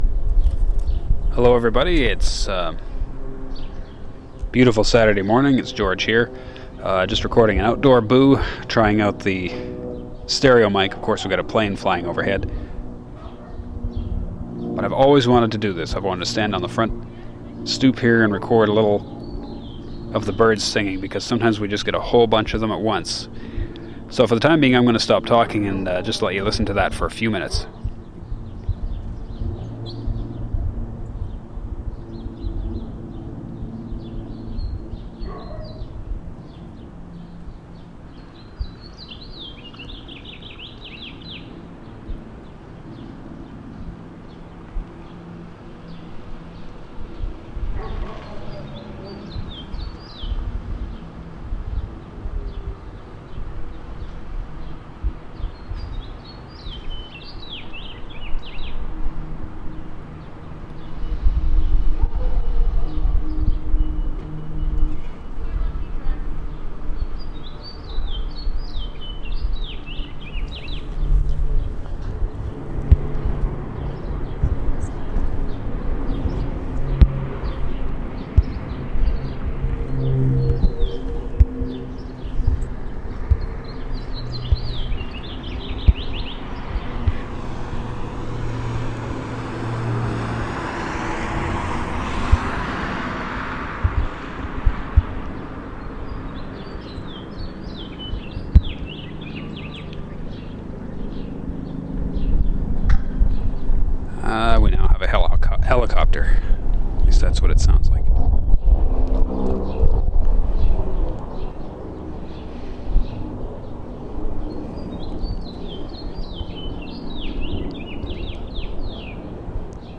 Outdoor boo with birds, a plane and a helicopter